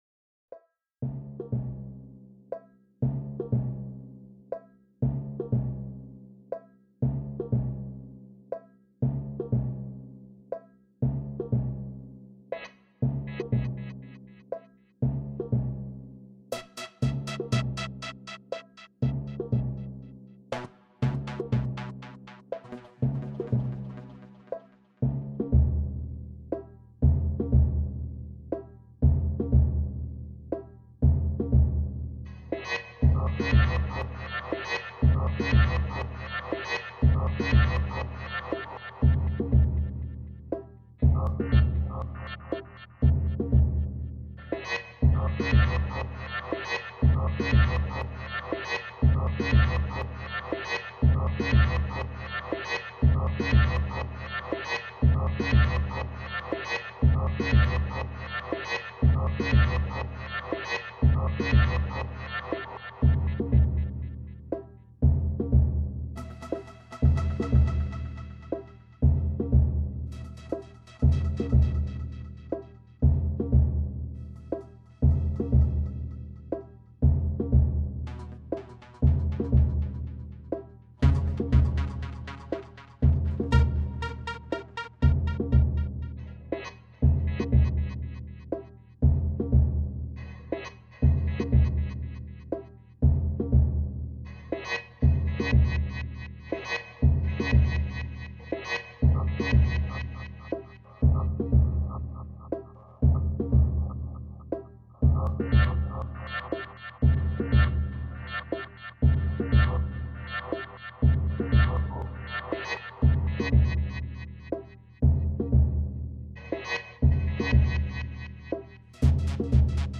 Cinematic Score